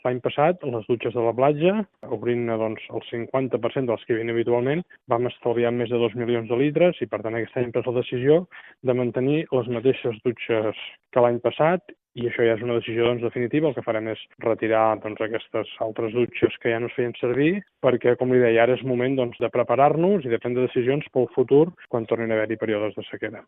El govern local ha anunciat que suprimirà la meitat de les dutxes i rentapeus de les platges, que només funcionaran de 10 a 21h. Ho ha anunciat l’alcalde Marc Buch en declaracions a Ràdio Calella TV: